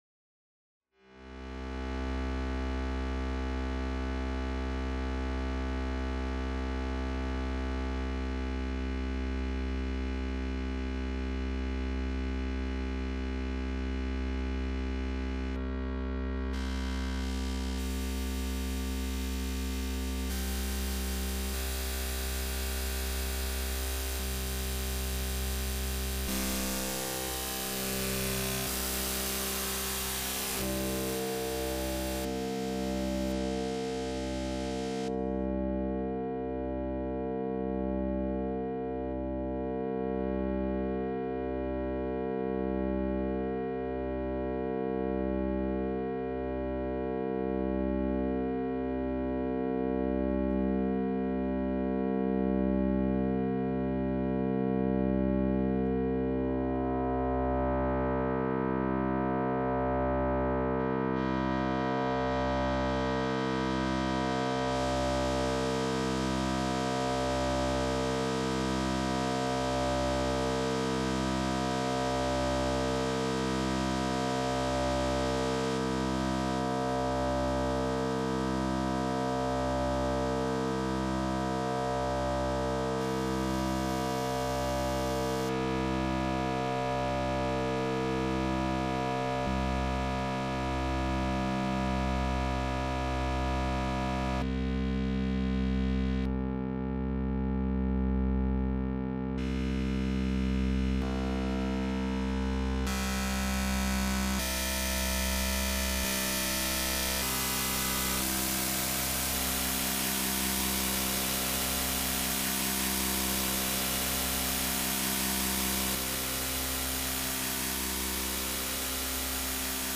M4L synth test